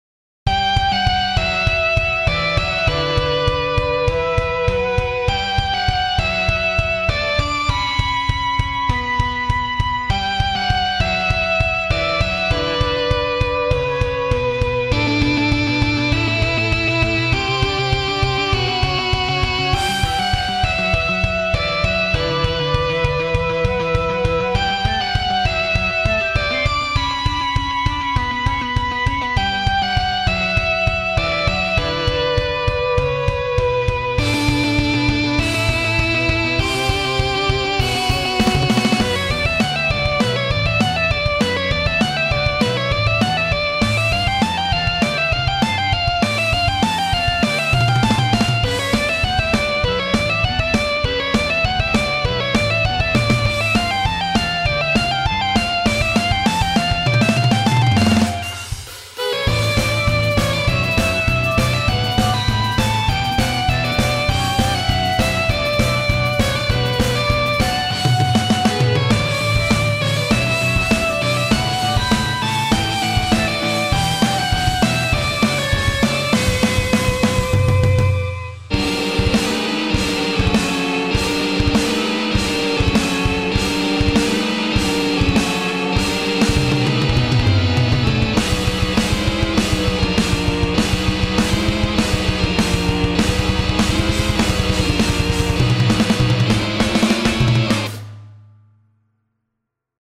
BGM
ショートロック